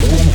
Key-bass_10.3.1.wav